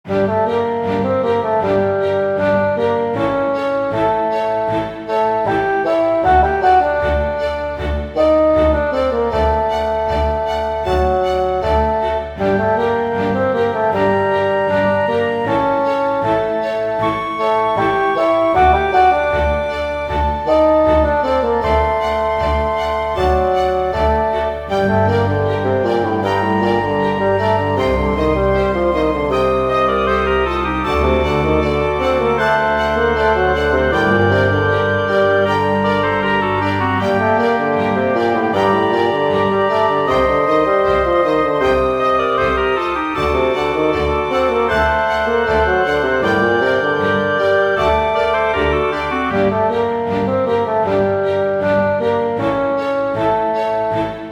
イメージ：洞窟 冒険   カテゴリ：RPG−外・ダンジョン